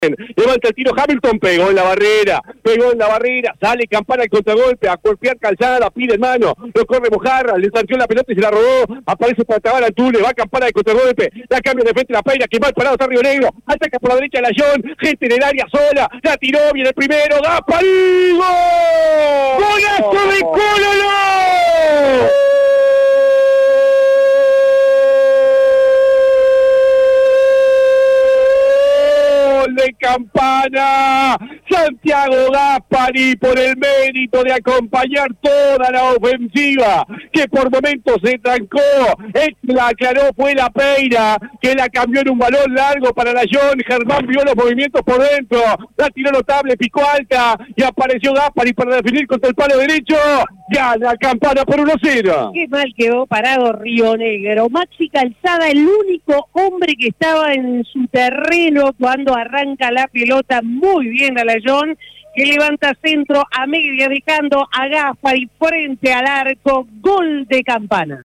Campana avanza en el Torneo Clausura al vencer como visitante a Rio Negro por 2 goles a 0. Reviva los goles